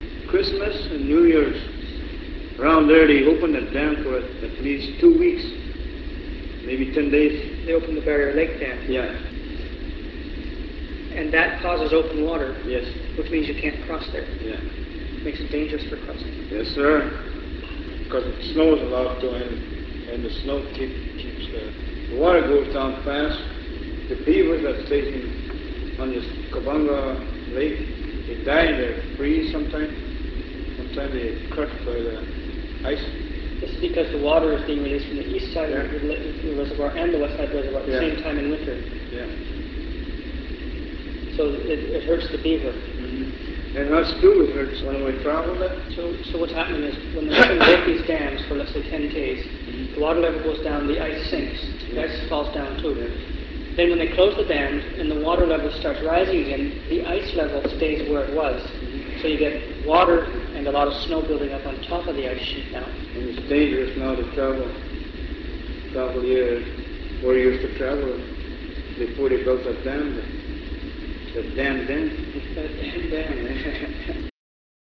The construction of hydro-electric dams have impacted the traditional activies of many First Nation communities. The following are stories from Barriere Lake.